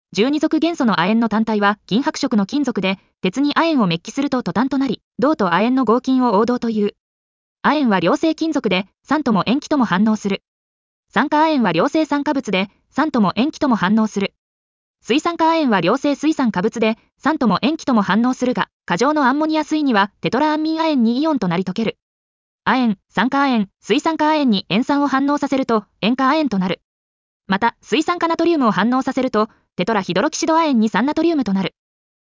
• 耳たこ音読では音声ファイルを再生して要点を音読します。
ナレーション 音読さん